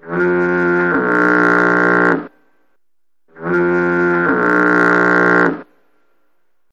Fog Horn.mp3